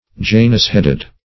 Janus-headed \Ja"nus-head`ed\, a.